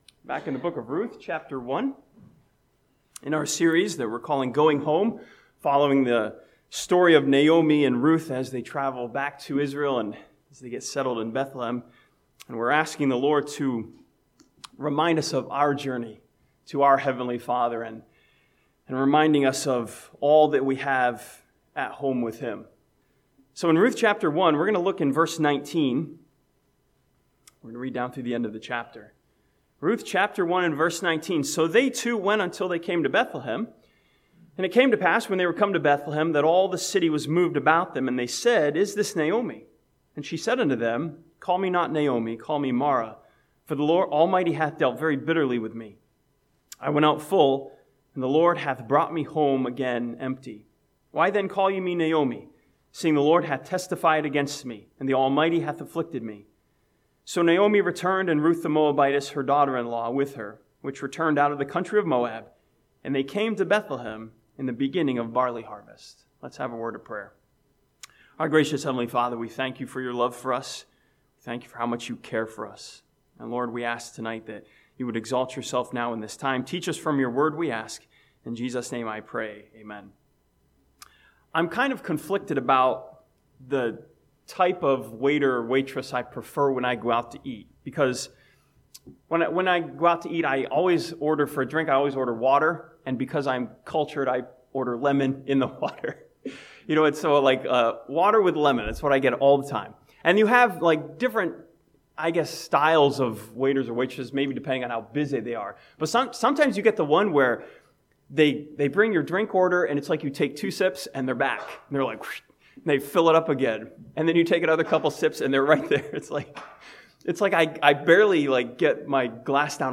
This sermon from Ruth chapter 1 learns that our faithful heavenly Father offers to make full again those who are empty.